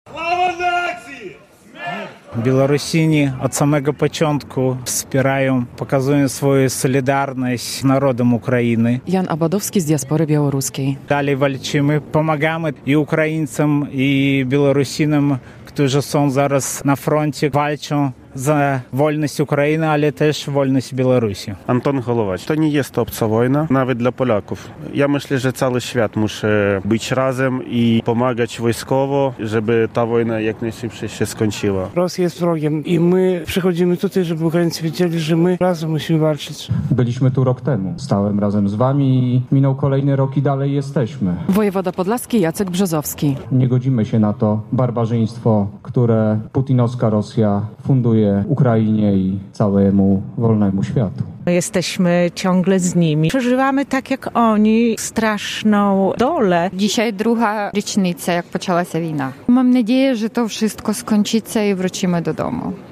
W Białymstoku na Rynku Kościuszki odbyła się w sobotę (24.02) akcja "Razem przeciwko rosyjskiej agresji" w drugą rocznicę wybuchu wojny w Ukrainie.
relacja